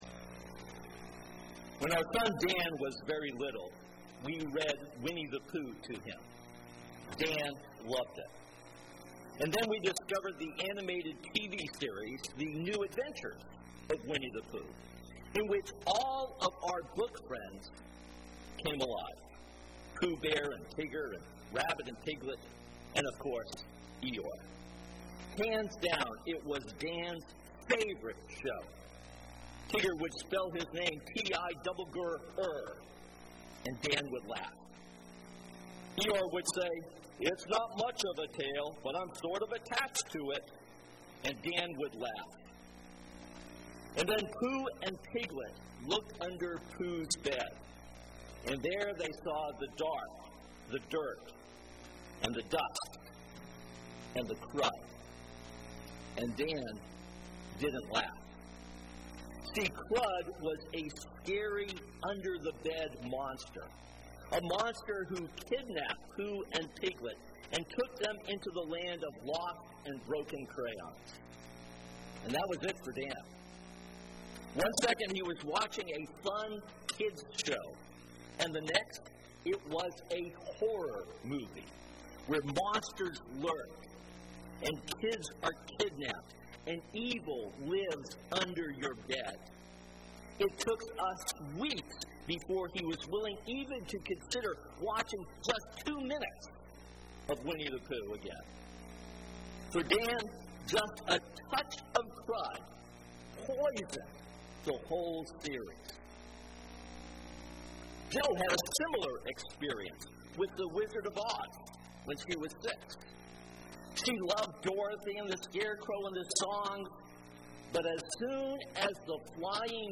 This sermon begins our new series and is based on 1 Corinthians 11:23-29.